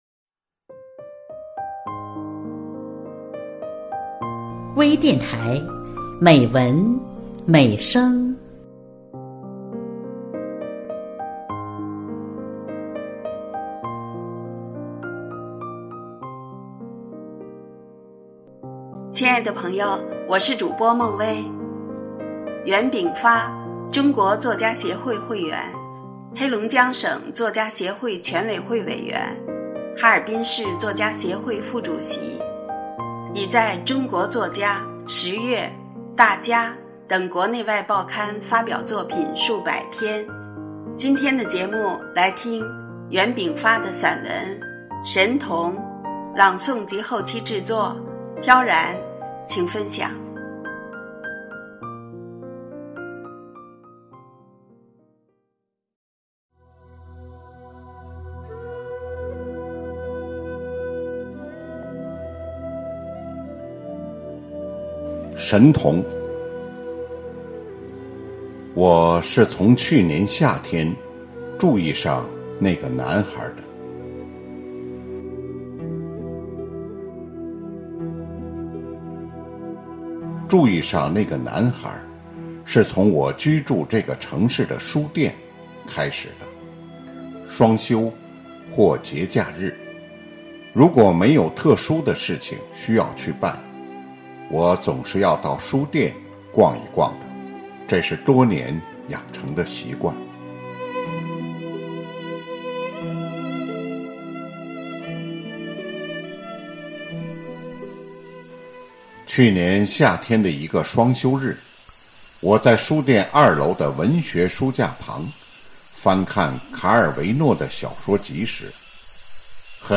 多彩美文  专业诵读
美文美声    声音盛宴